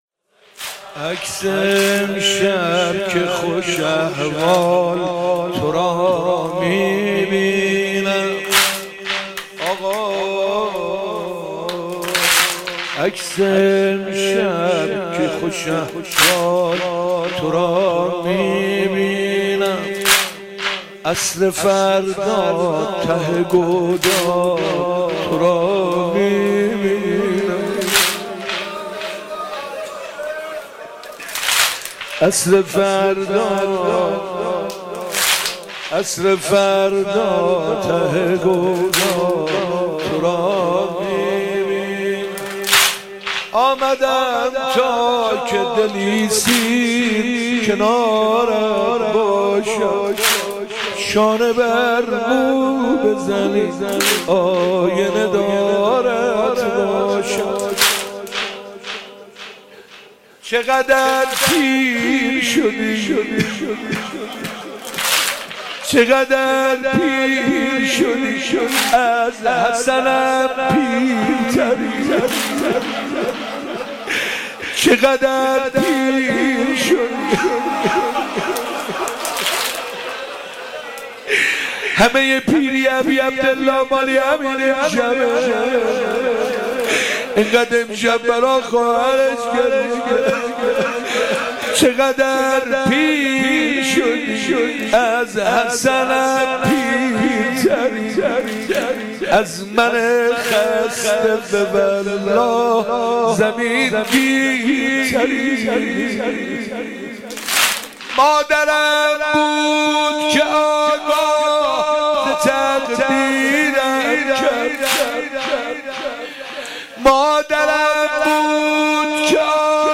محرم 97